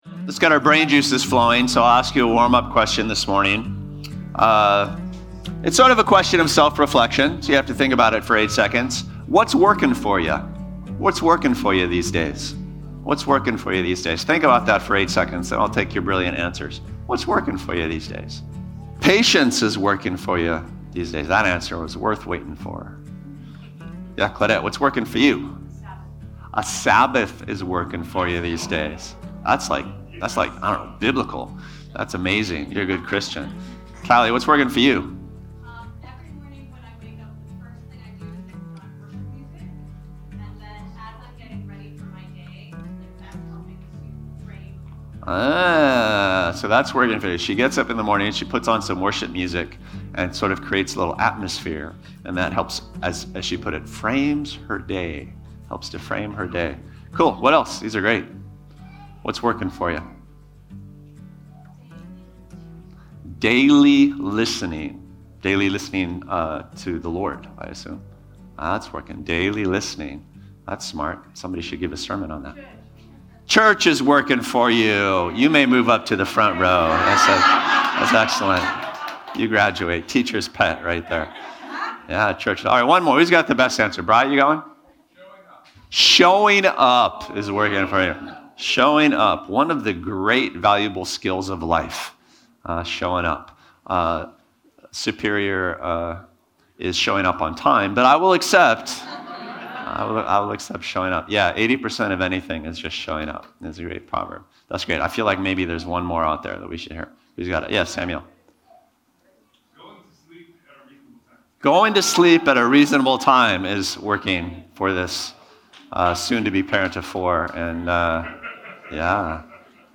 Podcasts of Bluewater Mission sermons, updated weekly.